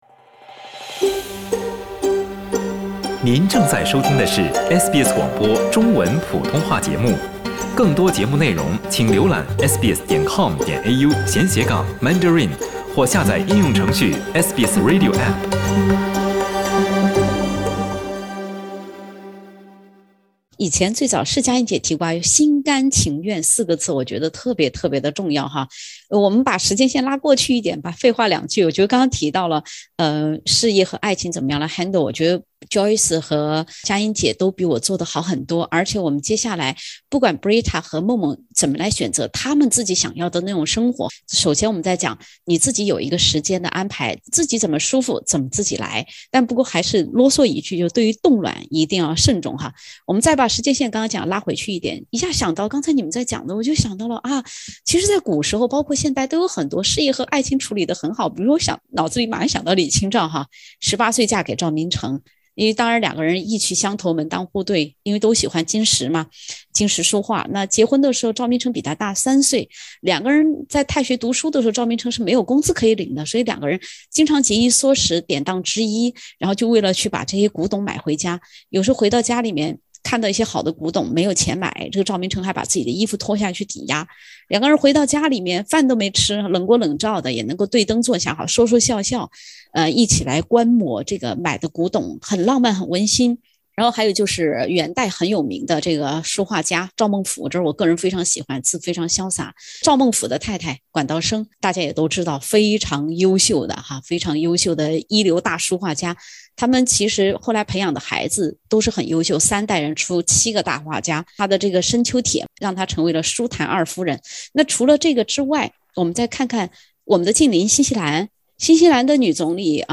哪怕家庭生活再匮乏的人，当有一天这种对家庭的需求来了，弯道超车只是分分钟的事。(点击封面，收听完整采访）